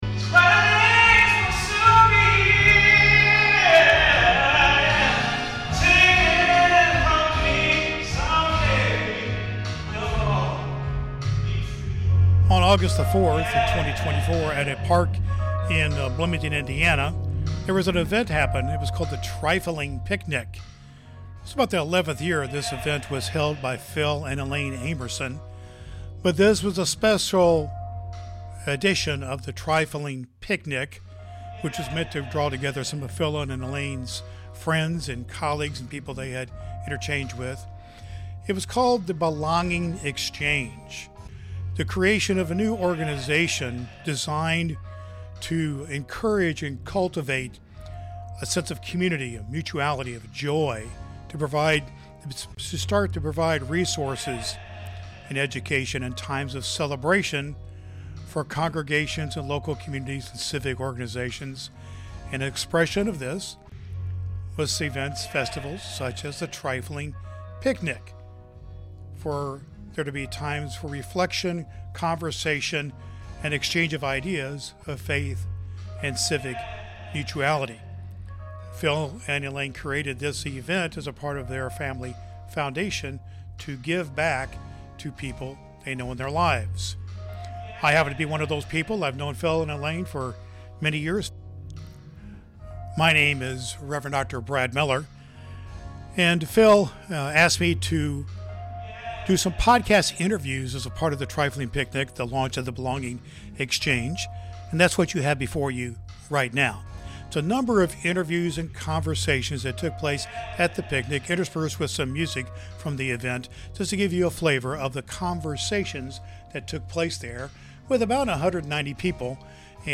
This unique event marked the launch of the "Belonging Exchange," a new organization aimed at fostering community, mutual joy, and support through diverse, inclusive interactions. Join us as we delve into the insightful experiences and aspirations shared by those in attendance.